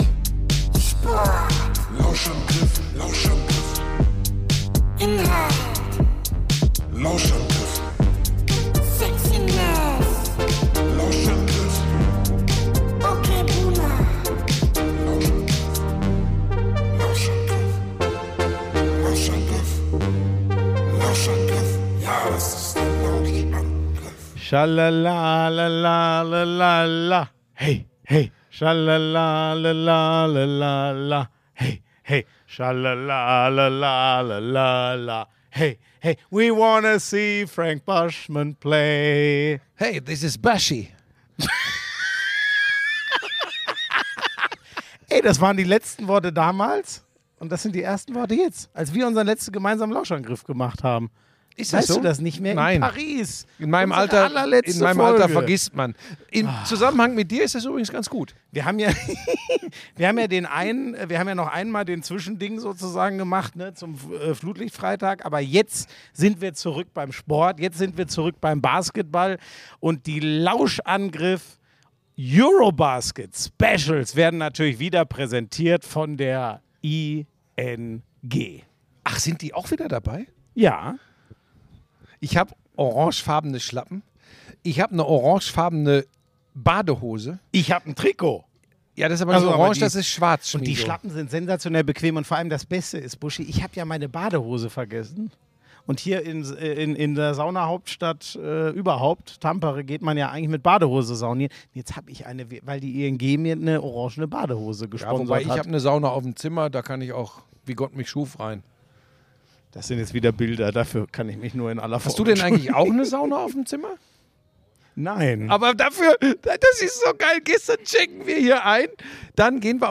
Diskussion um die Dreierquote. Und es wird gesungen... endlich wieder!